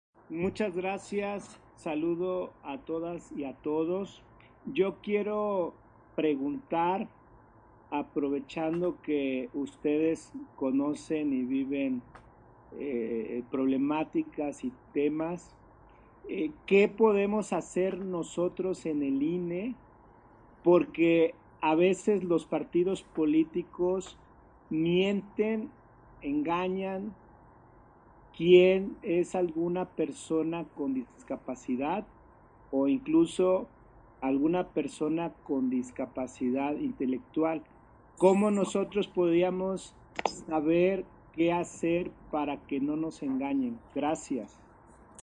Intervenciones de Consejeras y Consejeros del INE